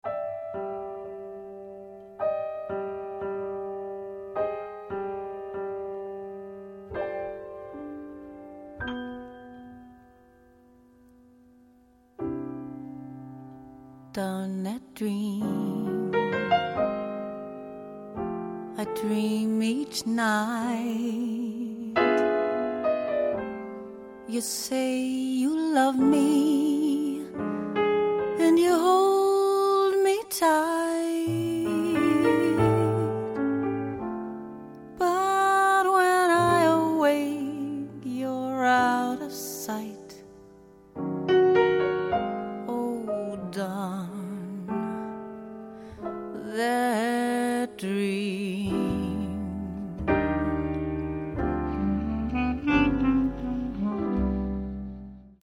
A night of jazz to remember.